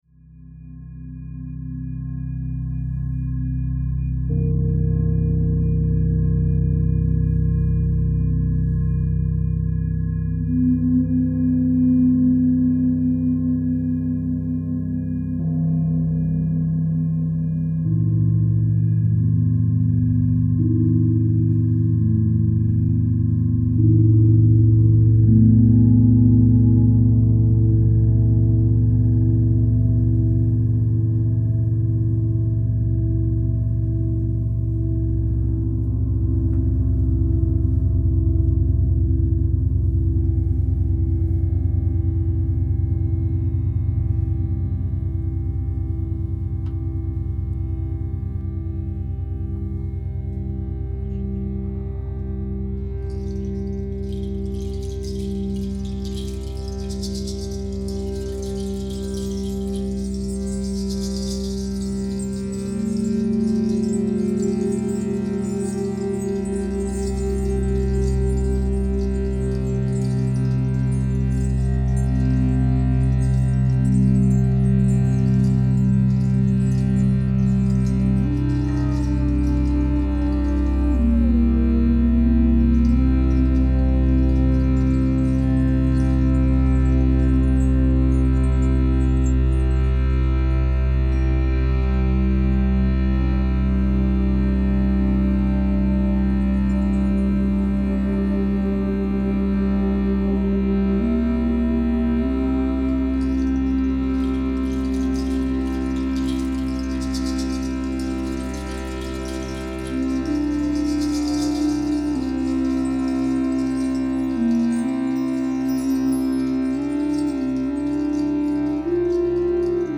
Mallets
multidimensional ambient meditation music